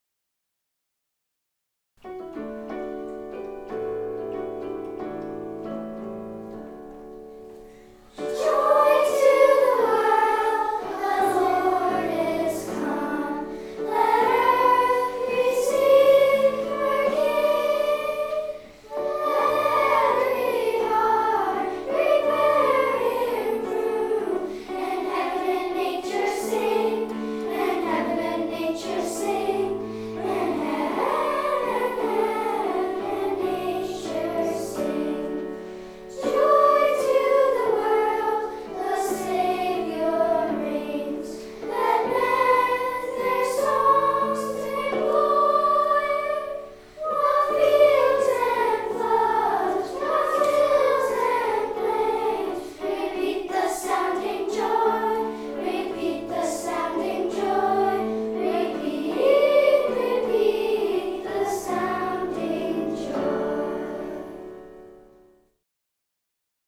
Living Nativity 2013 - Joy to the World